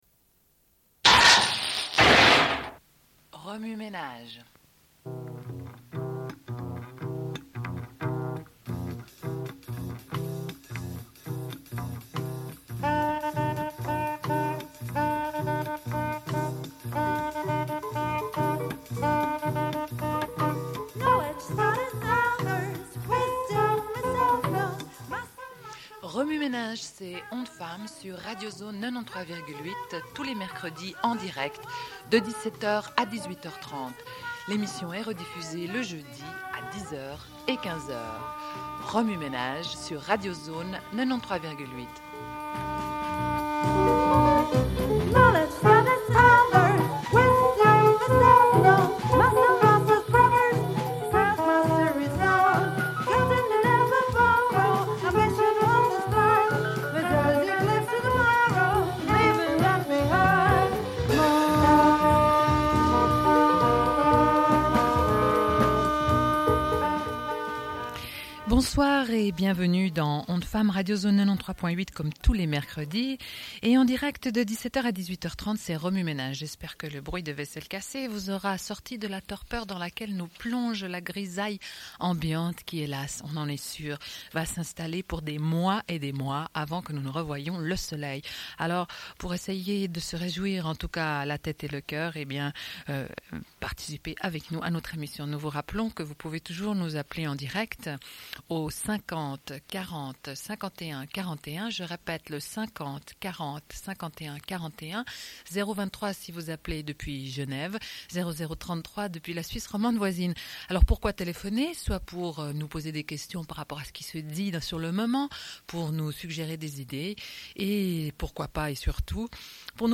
Une cassette audio, face A31:32